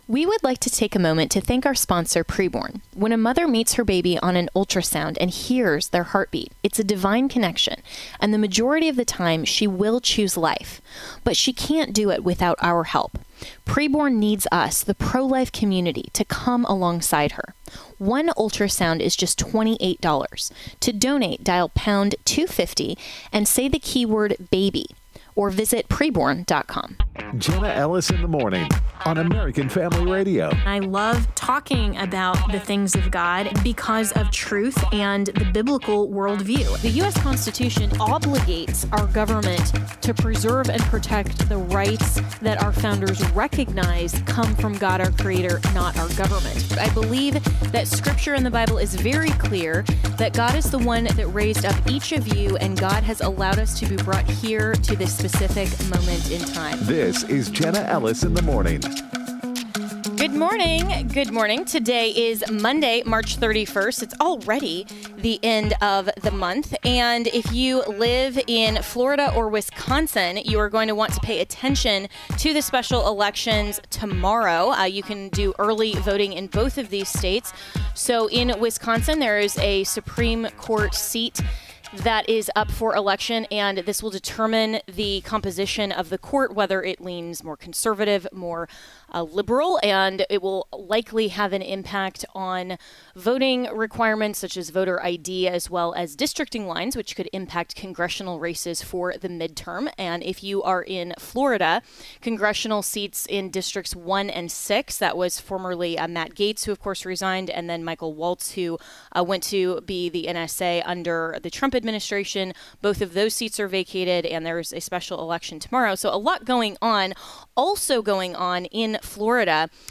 Florida Attorney General James Uthmeier joins the program to explain his position on Florida's ban on firearms for those under 21 years of age.
Author and filmmaker Dinesh D'Souza breaks down the Signal fiasco and what might be the real agenda of the left.